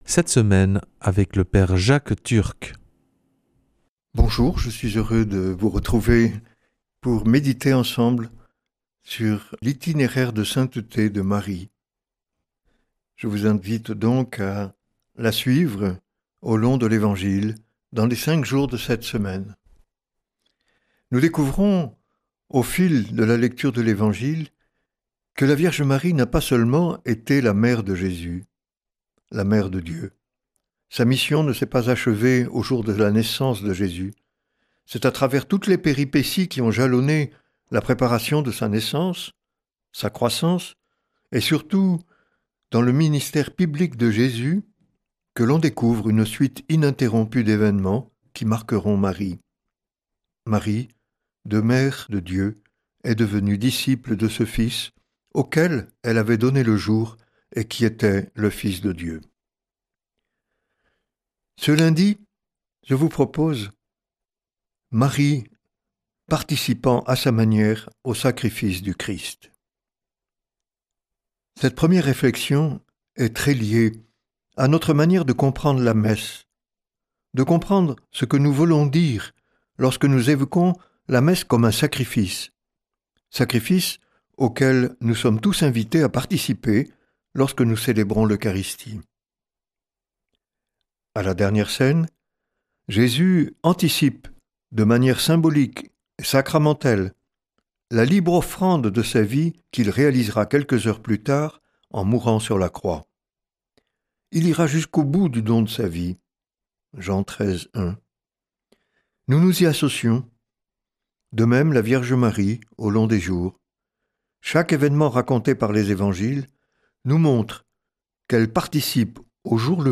lundi 13 octobre 2025 Enseignement Marial Durée 10 min
Une émission présentée par